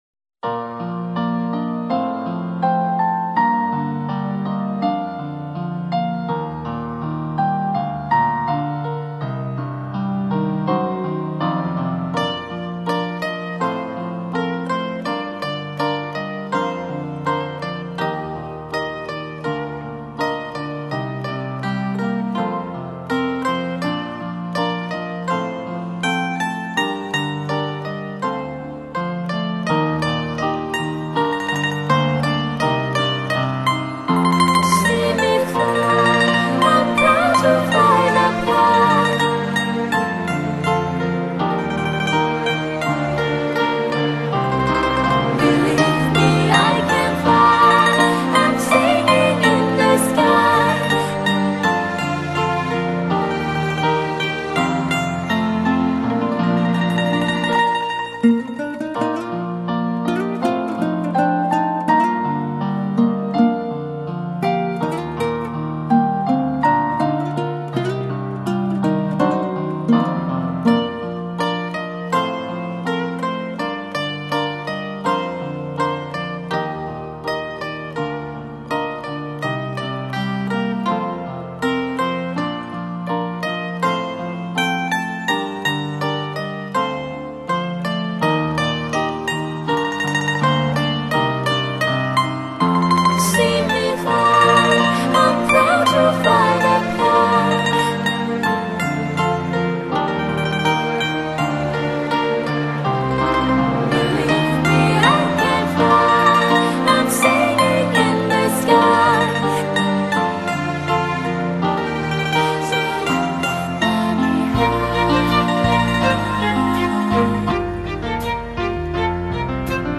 柳琴独奏
上海 各大专业文艺团首席乐队伴奏